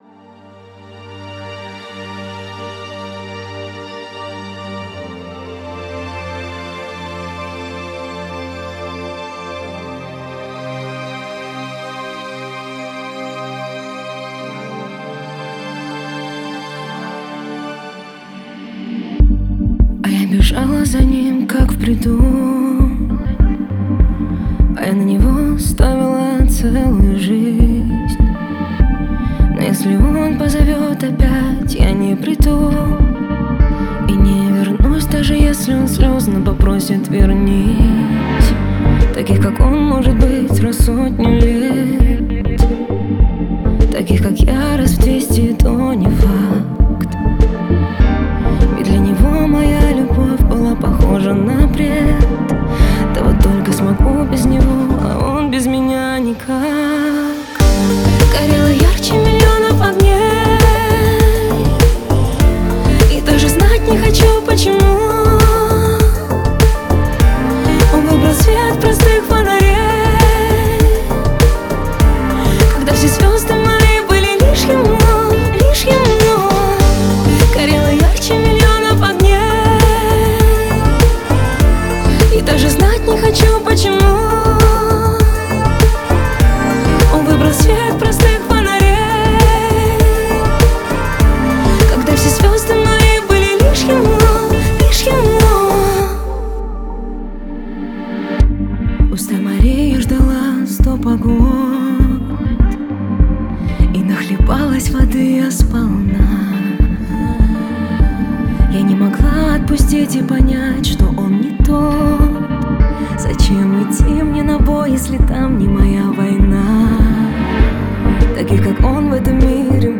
• Жанр: New Rus / Русские песни